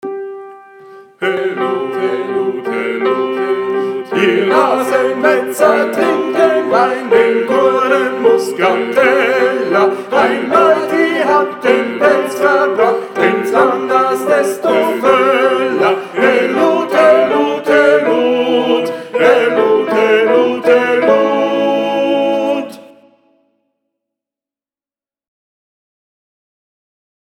19 Helut ihr zecher ALLE STIMMEN.mp3